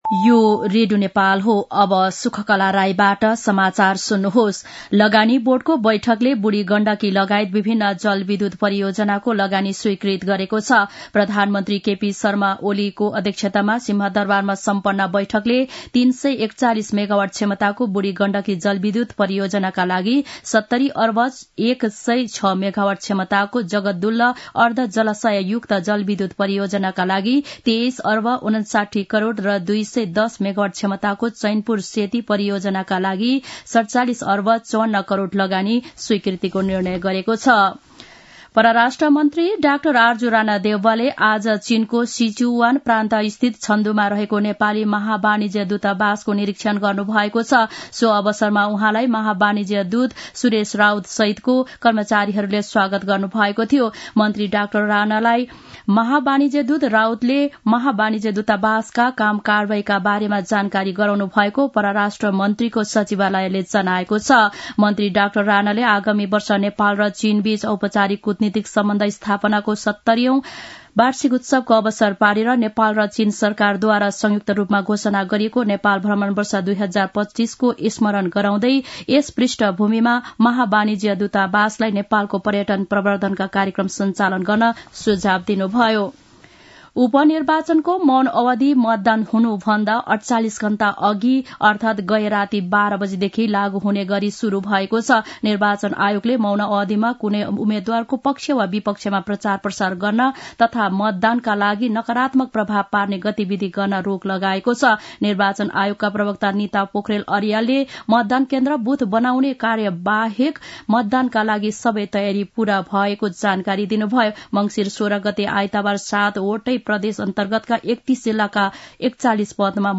दिउँसो १ बजेको नेपाली समाचार : १५ मंसिर , २०८१
1-pm-nepali-news-1-11.mp3